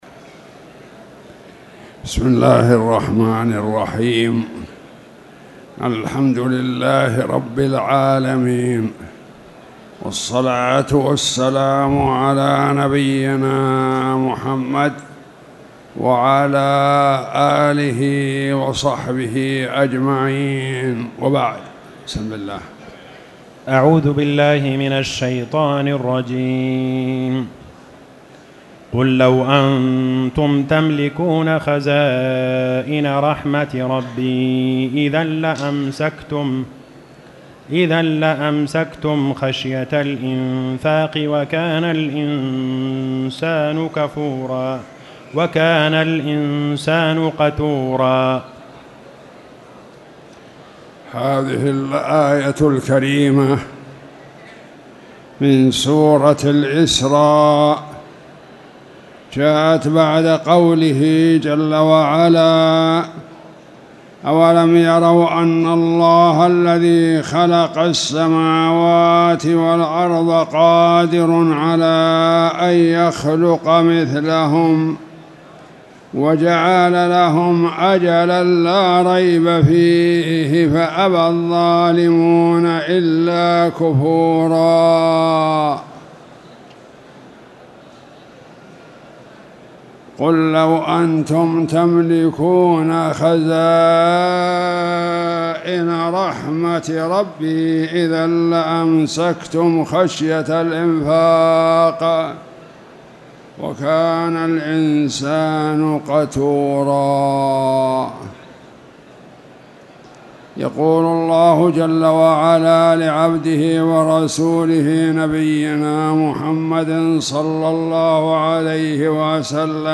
تاريخ النشر ١٥ ربيع الأول ١٤٣٨ هـ المكان: المسجد الحرام الشيخ